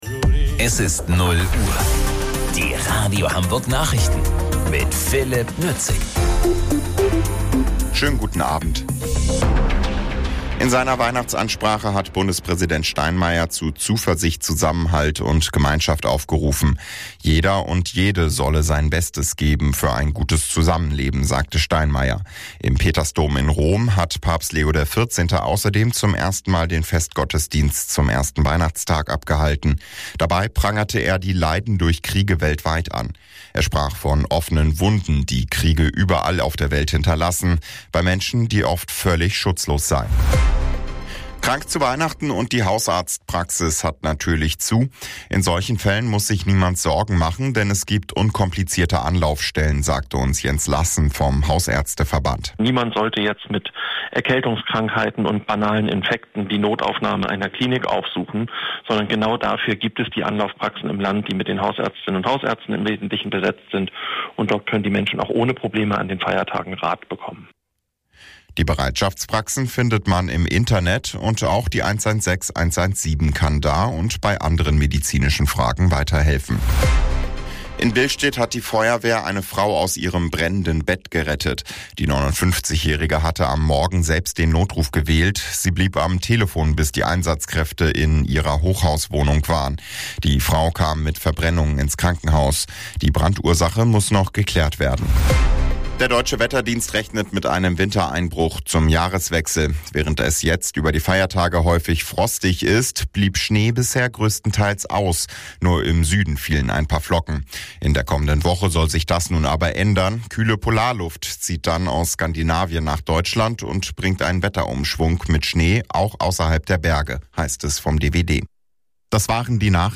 Radio Hamburg Nachrichten vom 26.12.2025 um 00 Uhr